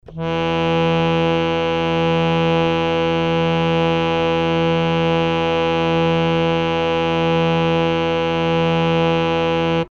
harmonium
E2.mp3